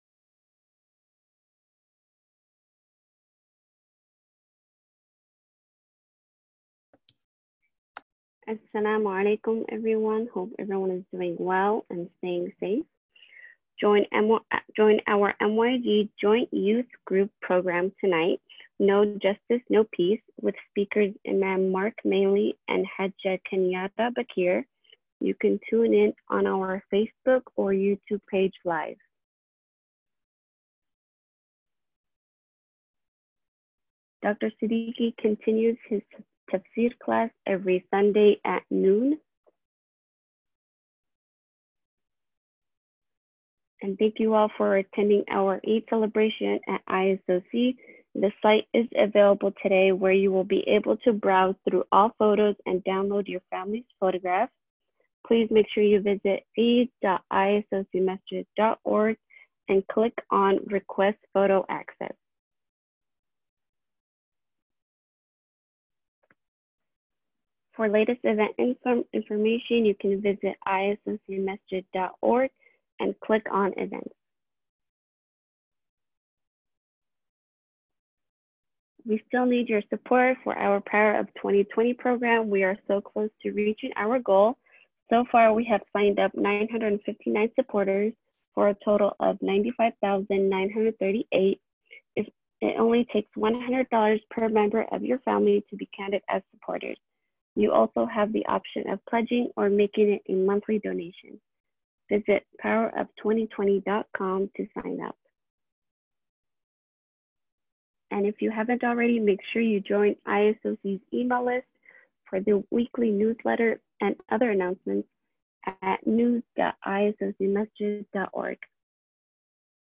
Jumu'ah Message